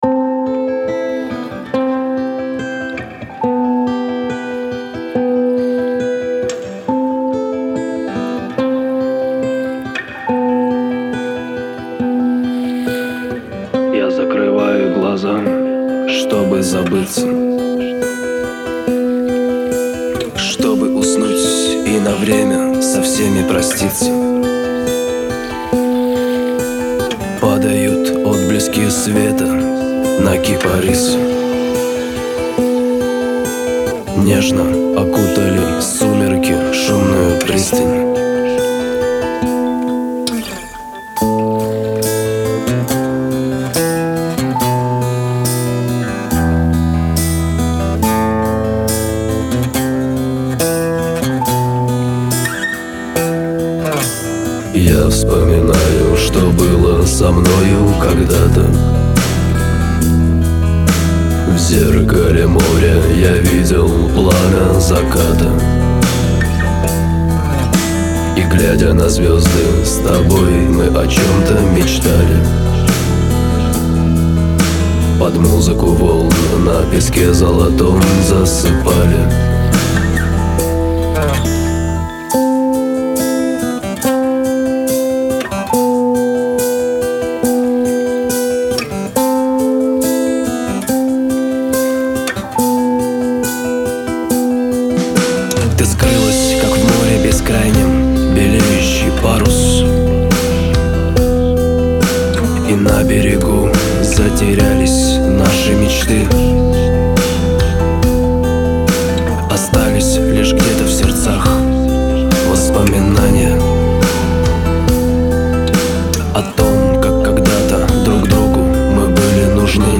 --9835-_ДВОРОВЫЕ_ПОД_ГИТАРУ--9835-_-Воспоминание-kissvk_